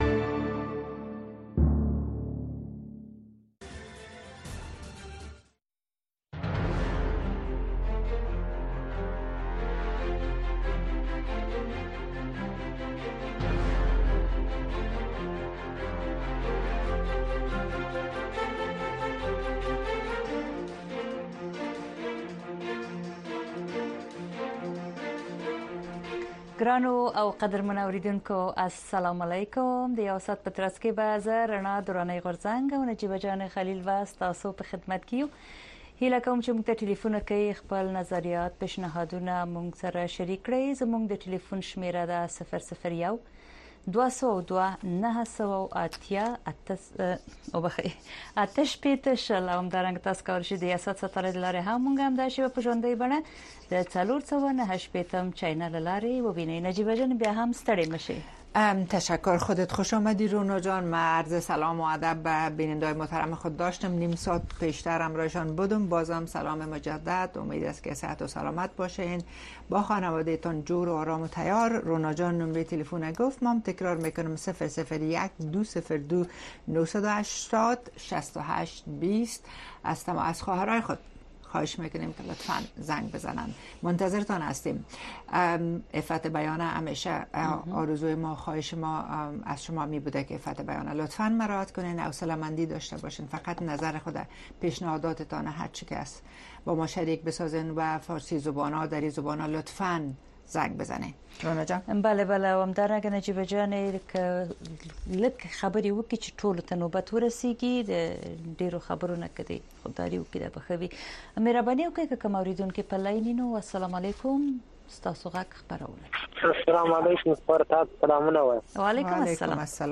ستاسې غږ خپرونه کې د امریکا غږ د اشنا رادیو اوریدونکي په مستقیمه توګه له مونږ سره اړیکه نیسي او د خپرونې د چلوونکو او اوریدونکو سره خپل نظر، اندیښنې او شکایتونه شریکوي. دا خپرونه په ژوندۍ بڼه د افغانستان په وخت د شپې د ۹:۳۰ تر ۱۰:۳۰ بجو پورې خپریږي.